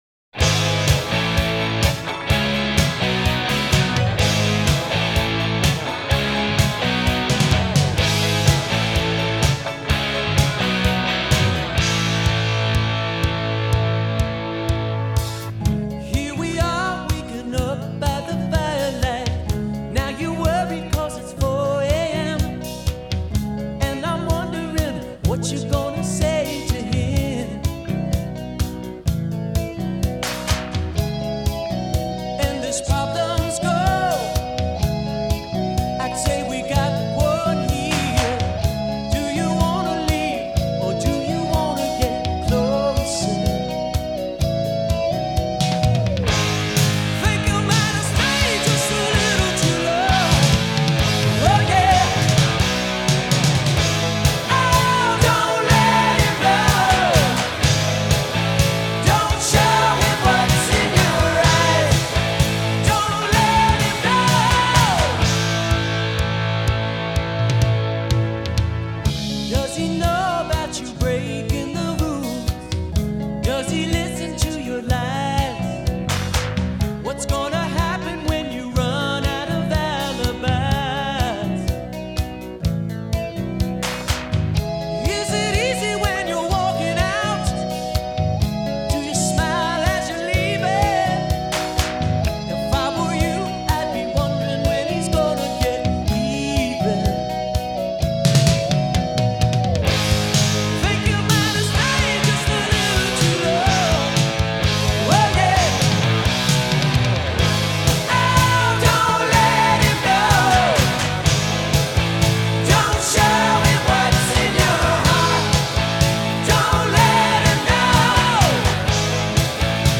A great slice of early ’80s rock.